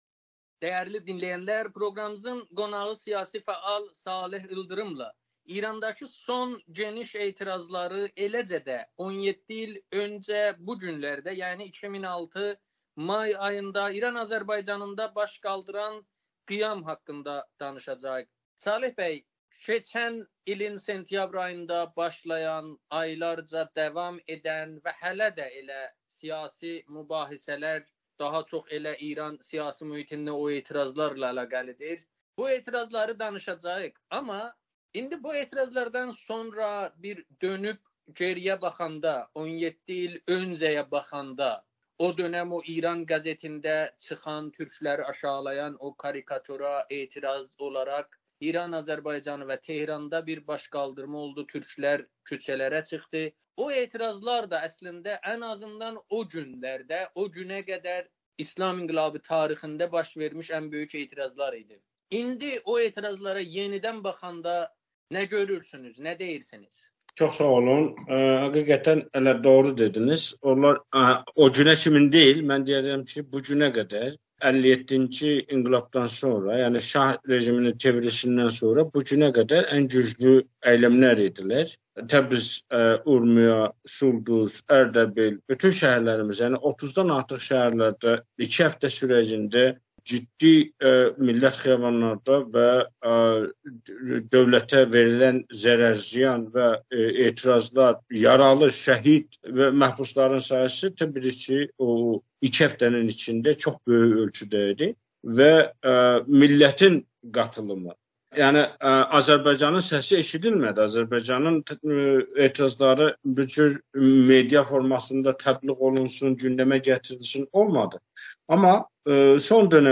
Amerikanın Səsi ilə söhbətdə 2006-cı ilin may ayında İran Azərbaycanında baş qaldıran böyük qiyam haqqında danışıb. O, may qiyamını sadəcə o tarixə qədər deyil, elə bu gün də İslam Respublikası tarixində meydana gəlmiş ən böyük kütləvi üsyan kimi dəyərləndirir.